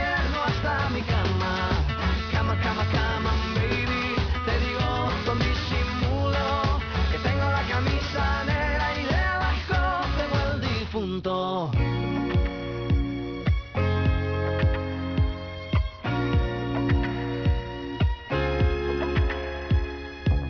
В зависимости от качества приема FM-сигнала, об уровне которого можно судить по соответствующему индикатору, коммуникатор автоматически выбирает моно или стерео режим.
Кроме того предусмотрена возможность записи с эфира. Качество записи можно назвать удовлетворительным.
o2-xda-flame-fm.wav